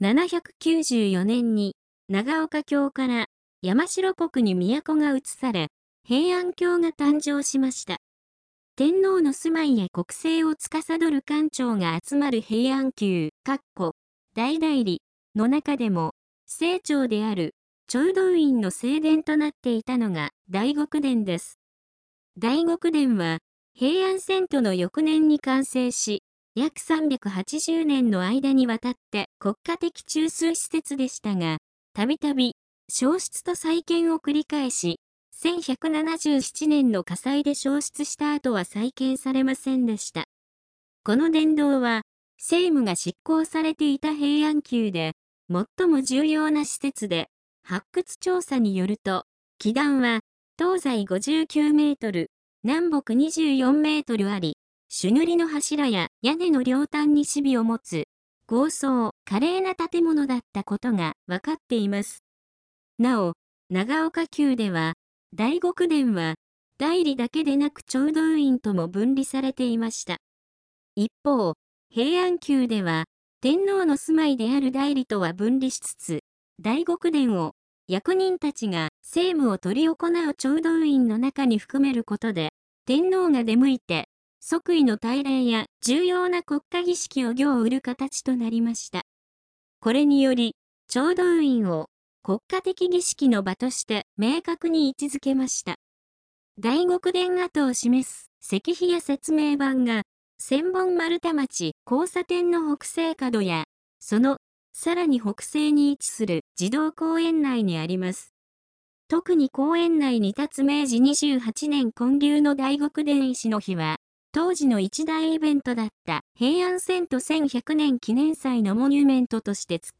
読み上げ音声